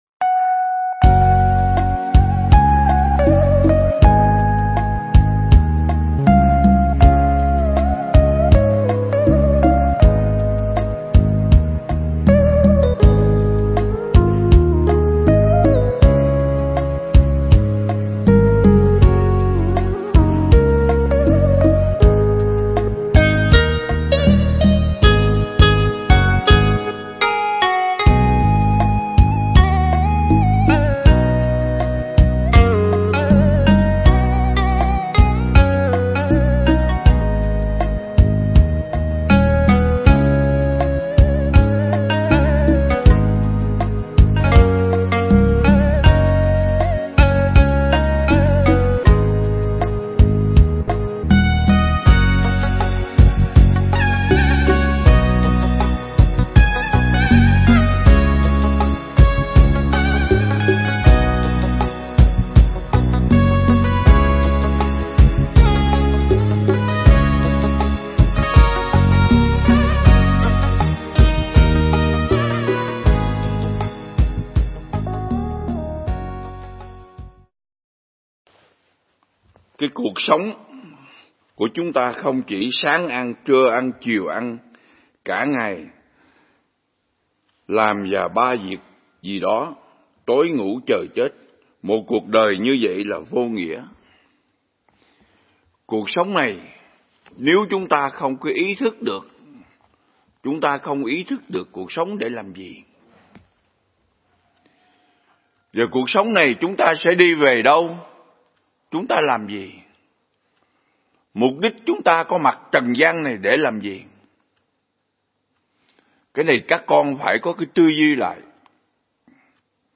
Pháp thoại